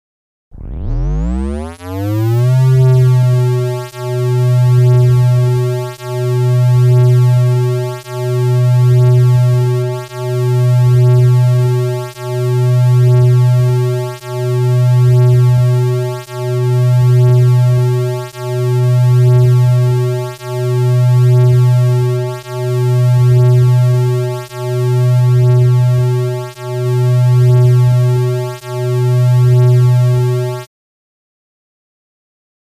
Low Frequency Modulation 2; Low-mid Frequency Rumble; Dual Pulsation, Steady, Close Perspective.